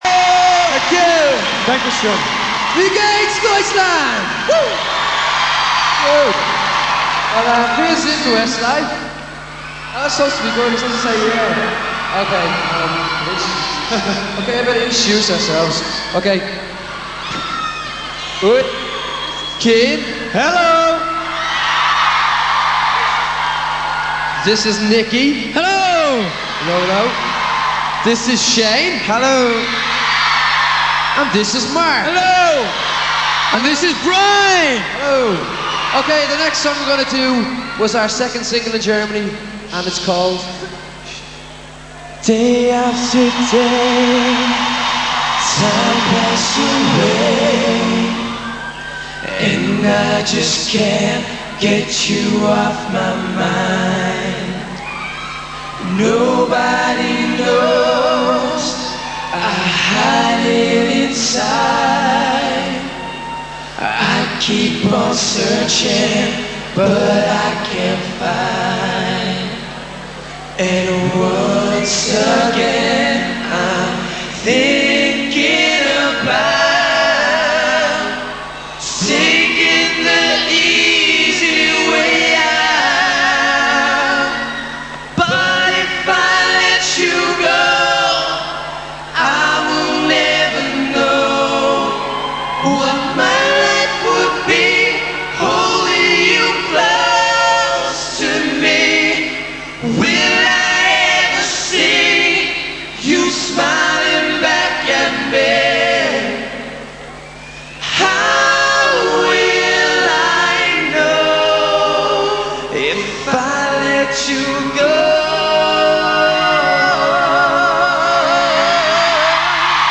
Accapella